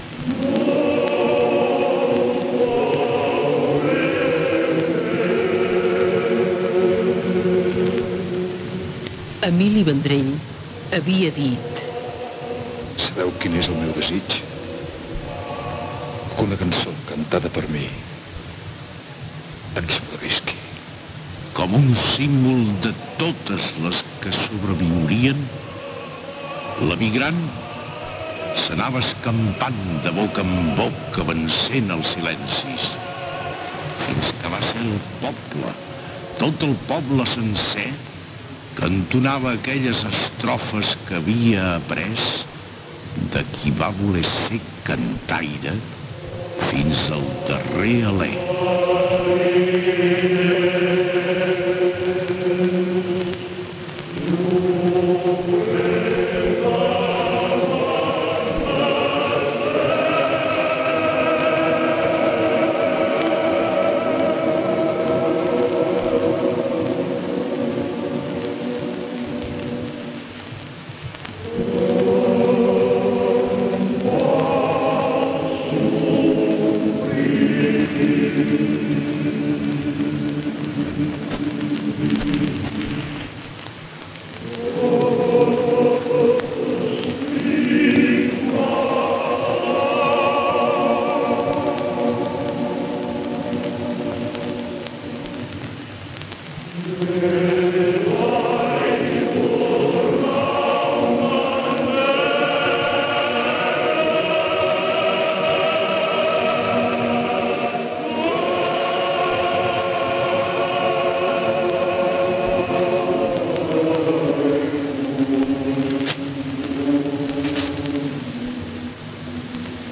Final del programa dedicat a Emili Vendrell, amb crèdits Gènere radiofònic Musical